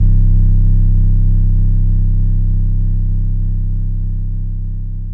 808s
SUB BOOM79.wav.wav